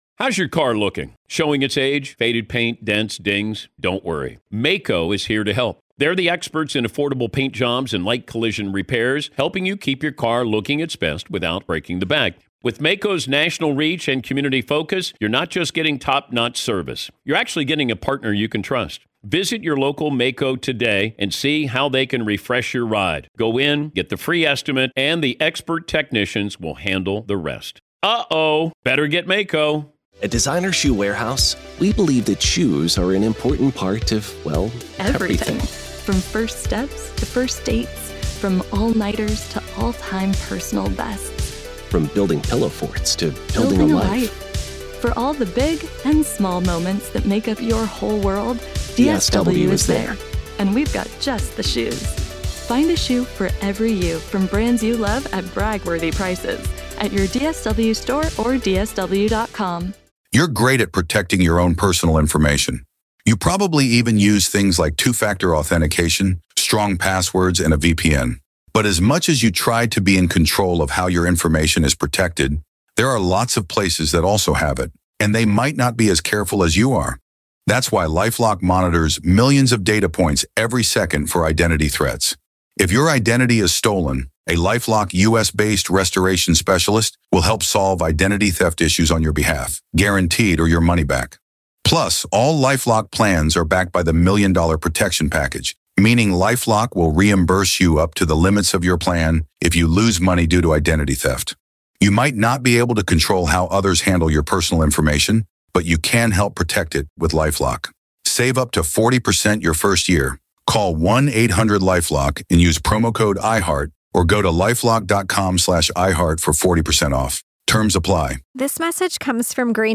What happens when the very systems meant to keep us safe fall asleep at the wheel? Dive into a jaw-dropping discussion on security failures, historical blind spots, and how generations forget hard-earned lessons in the pursuit of convenience.